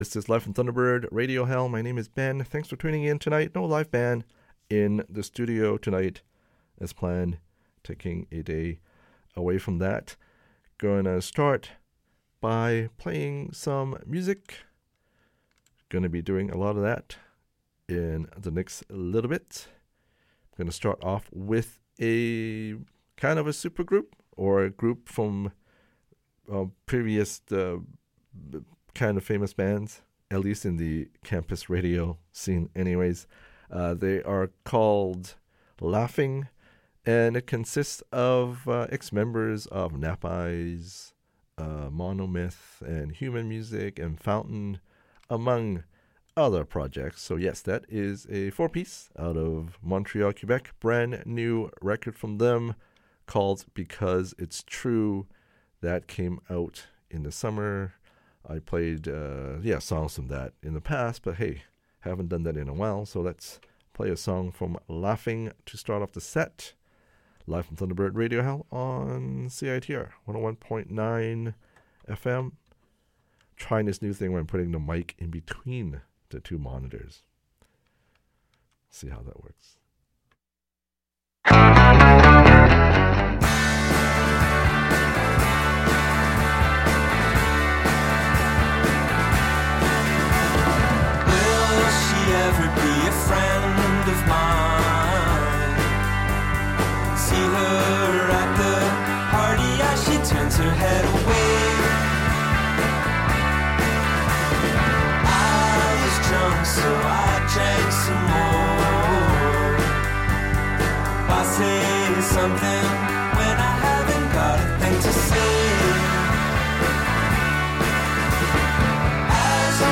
Music from Canada